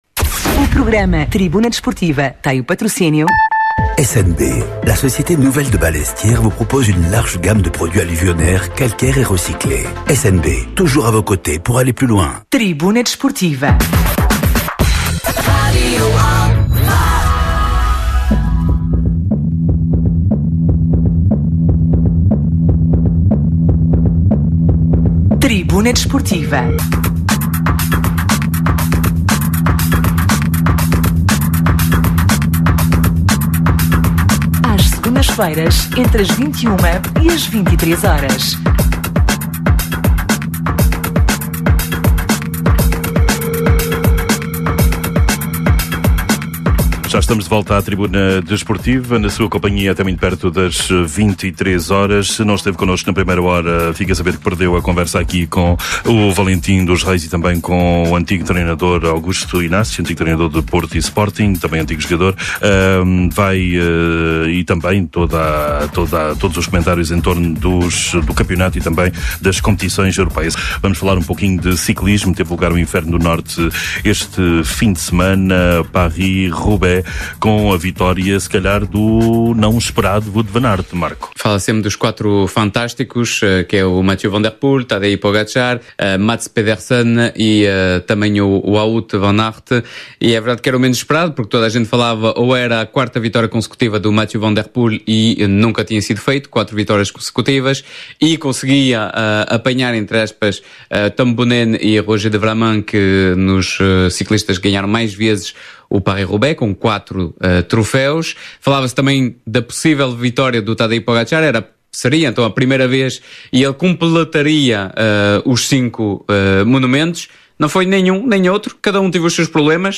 Atualidade Desportiva, Entrevistas.